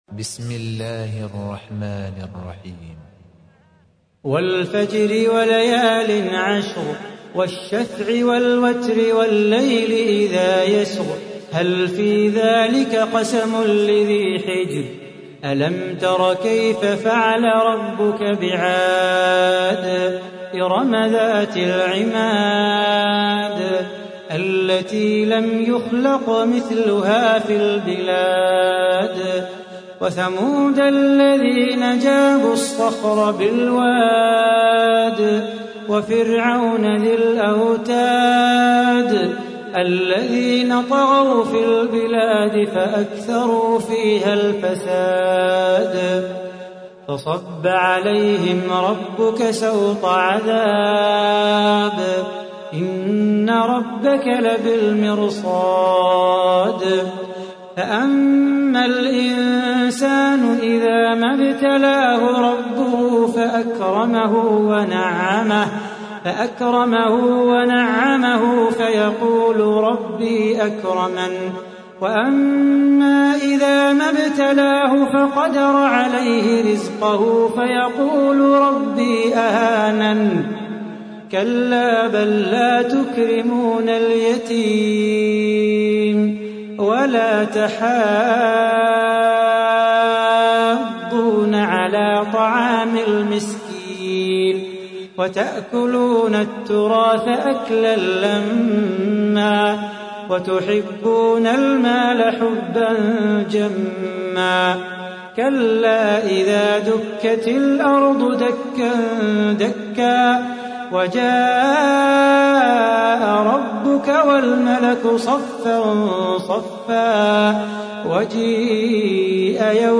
تحميل : 89. سورة الفجر / القارئ صلاح بو خاطر / القرآن الكريم / موقع يا حسين